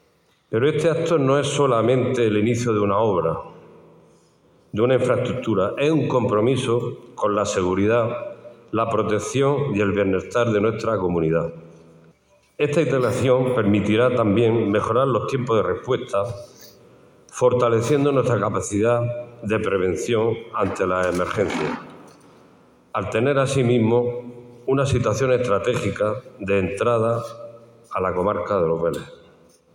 El presidente de la Diputación y el alcalde de Vélez-Rubio han presidido un acto que ha contado con los alcaldes y ediles de la comarca y la presidenta del Consorcio del Levante Almeriense